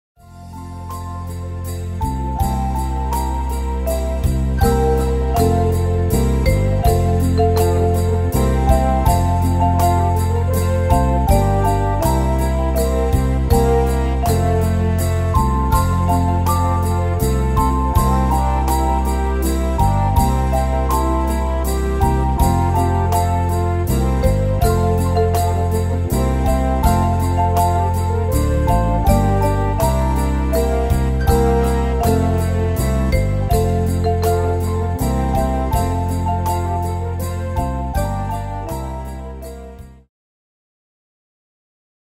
Tempo: 81 / Tonart: F-Dur